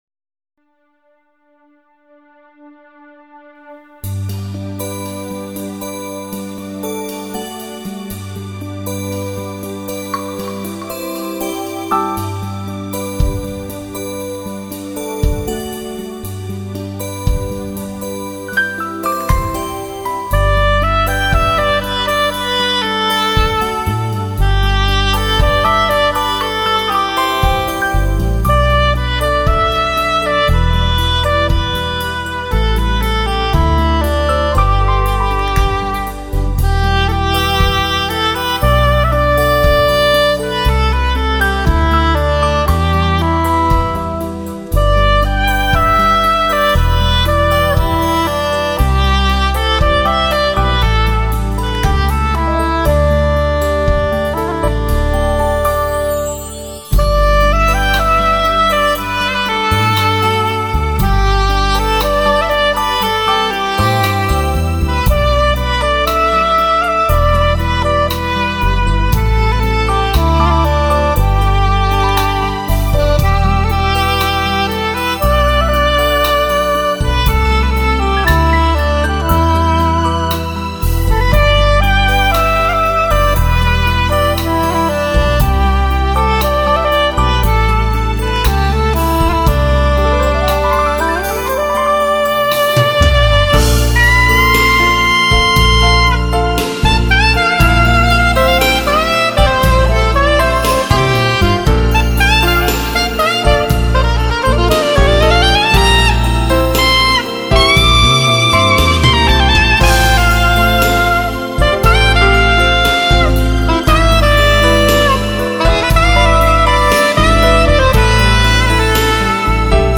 乐器演奏系列
为忙碌的现代人寻觅了一处真正而宁静的音乐田园。